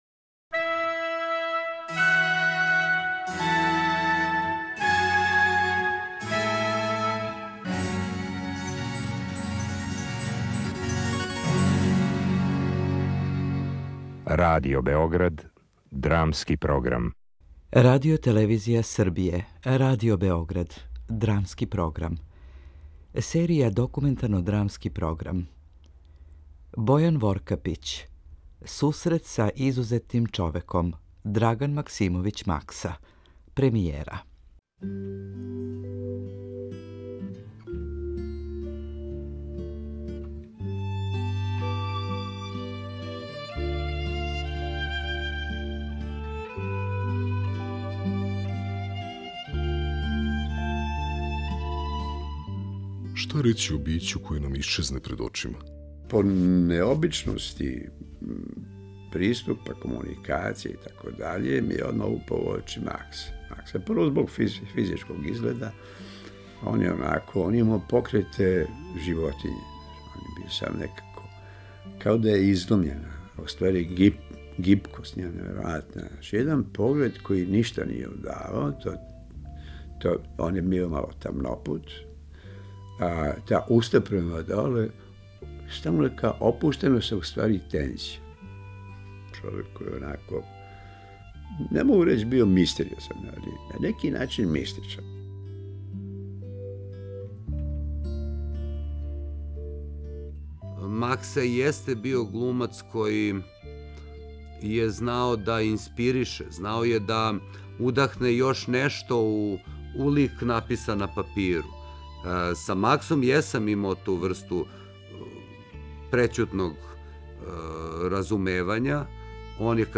Документарно-драмски програм